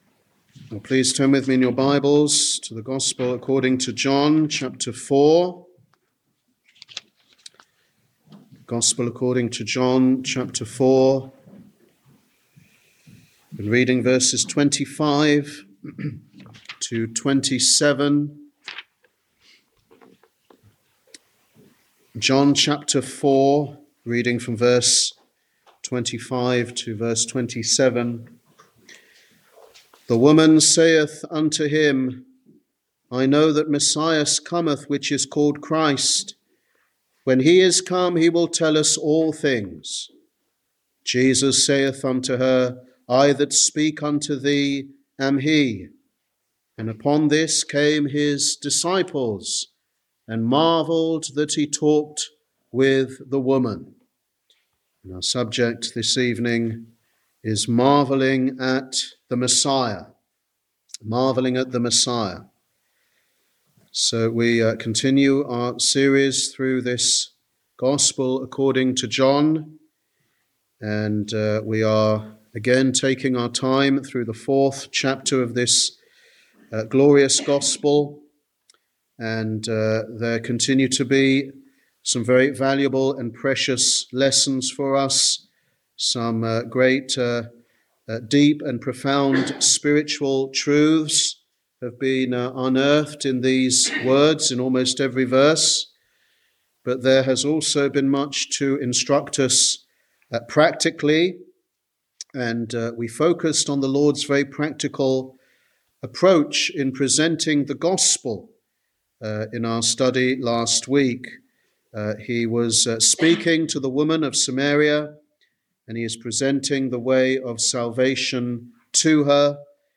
Sunday Teaching Ministry
Sermon